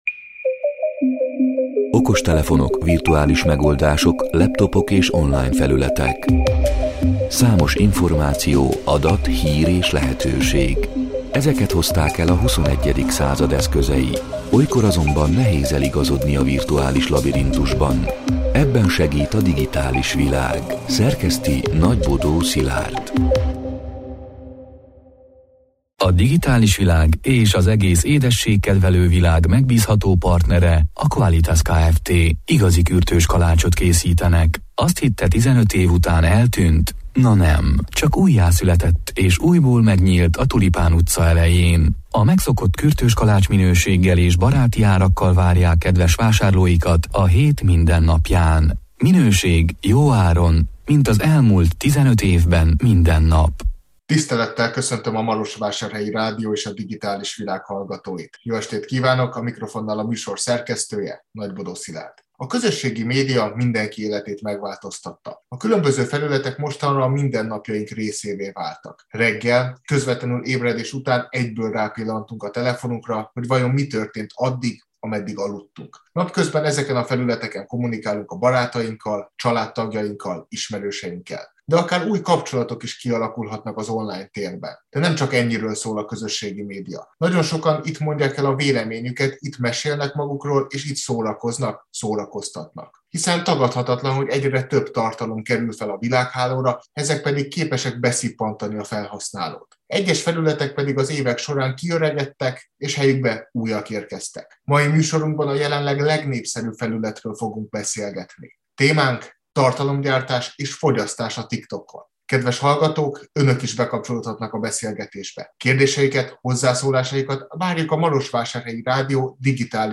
Mai műsorunkban a jelenleg legnépszerűbb felületről fogunk beszélgetni.